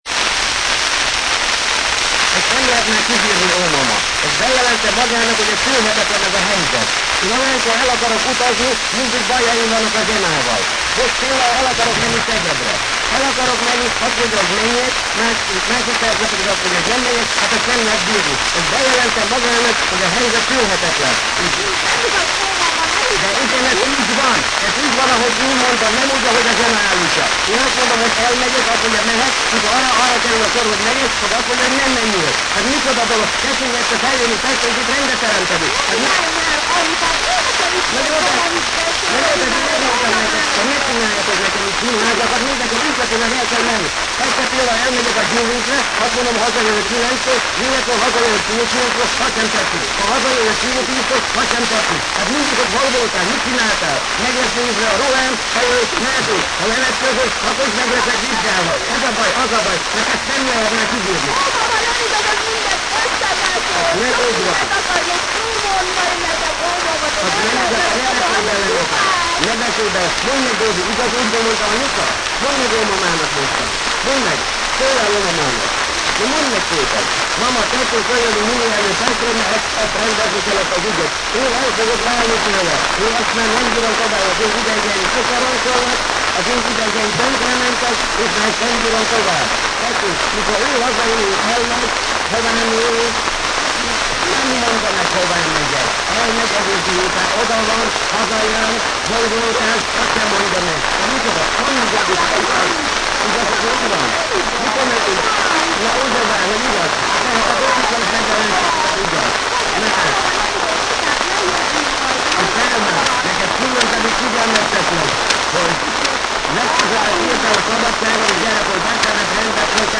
GRAMOGRAF AMAT�R CINKLEMEZ Royal Apollo Studio Budapest Kabos Gyula hangj�val
gramograf_cinklemez.mp3